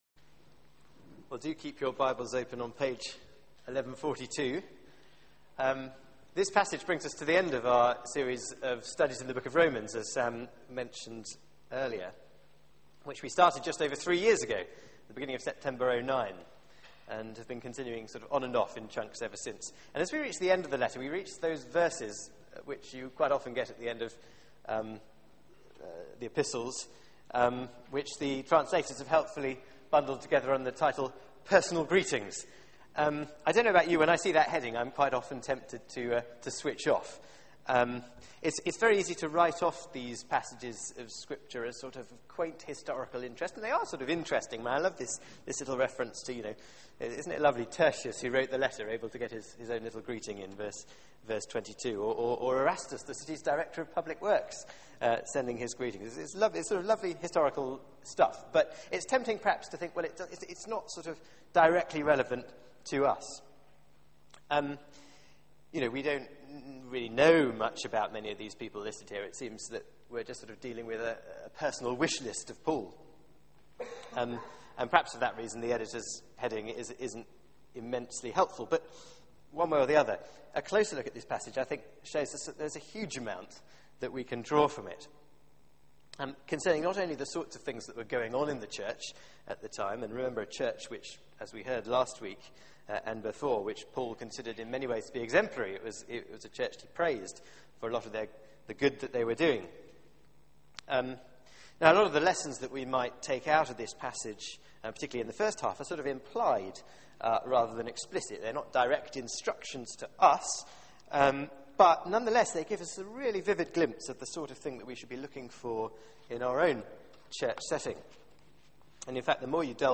Media for 6:30pm Service on Sun 28th Oct 2012 18:30 Speaker
Theme: Gospel partnership Sermon